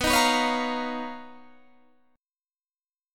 Listen to BM#11 strummed